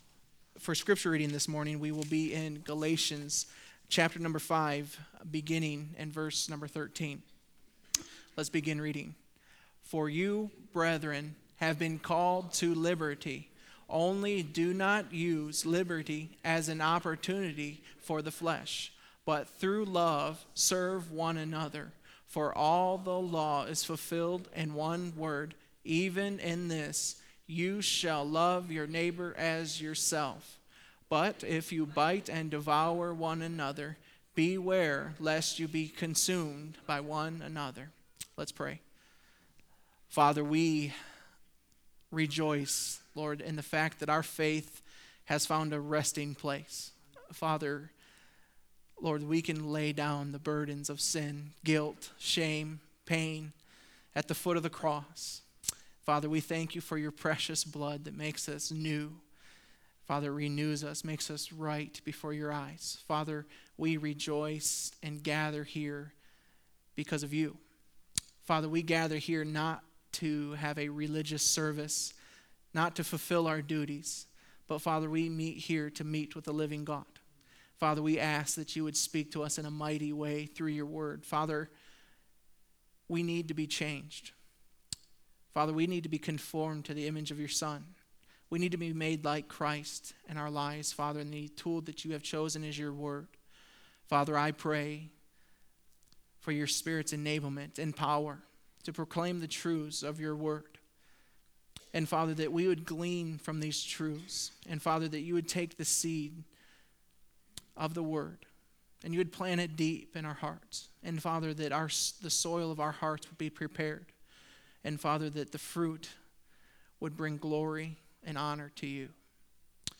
First Baptist Church of Fenton Sermons